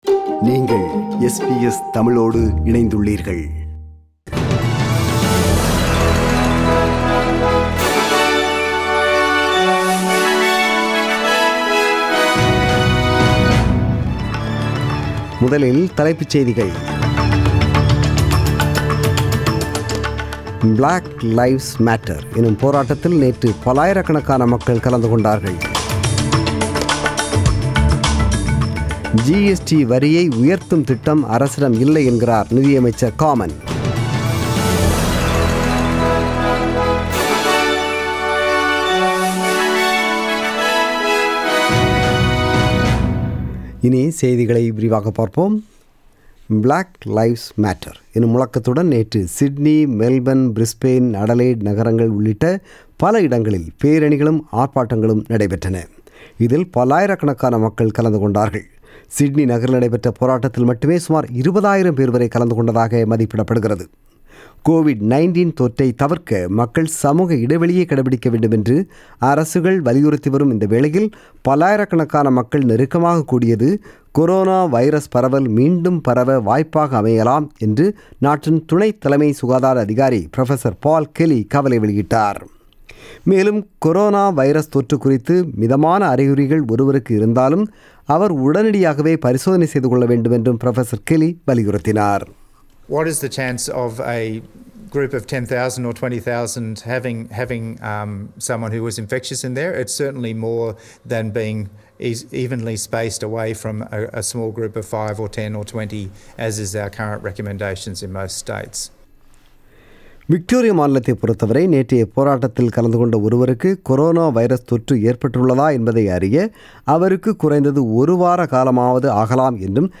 The news bulletin was broadcasted on 7 June 2020 (Sunday) at 8pm.